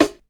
• 00's Original Hip-Hop Steel Snare Drum Sample F# Key 21.wav
Royality free steel snare drum tuned to the F# note. Loudest frequency: 1015Hz
00s-original-hip-hop-steel-snare-drum-sample-f-sharp-key-21-6Jc.wav